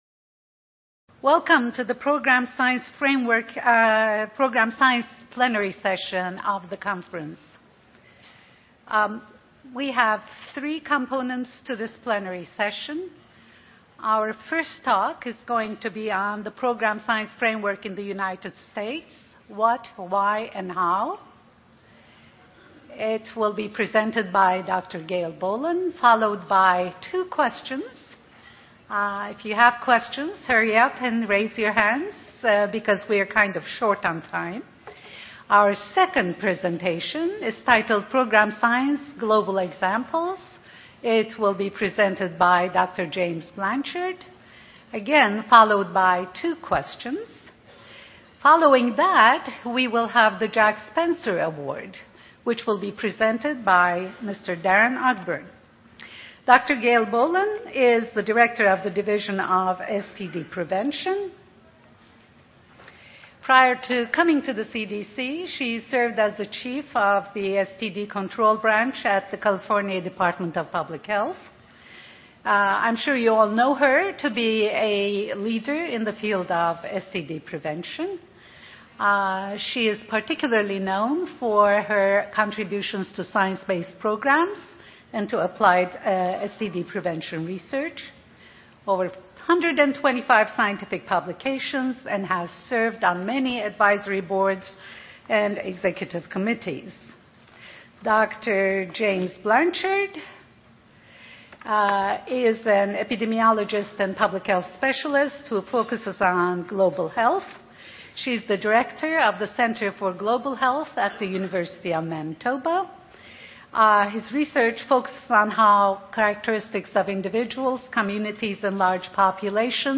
P1 Plenary Session 1: Program Science
Grand Ballroom
Welcoming Remarks